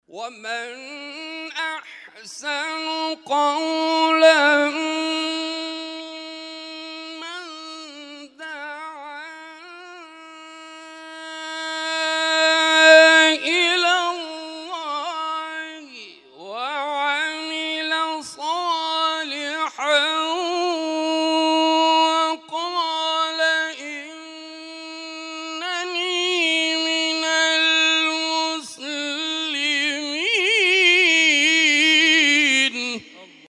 محفل هفتگی انس با قرآن در آستان عبدالعظیم(ع) + صوت